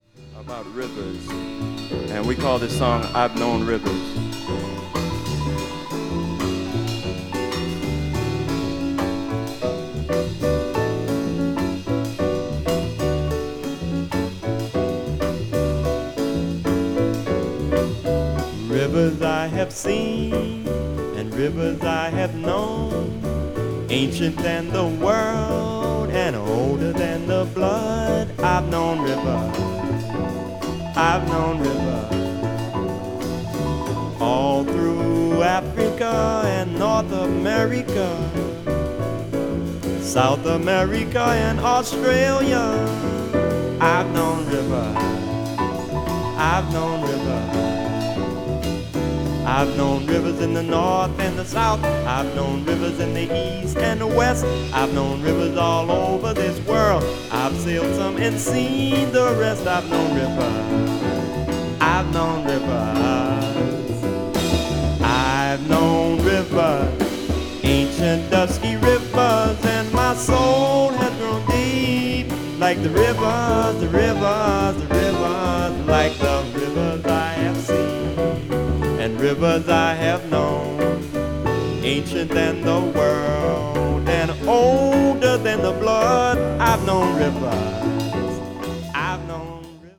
saxophonist
recorded live at the Montreux Jazz Festival in Switzerland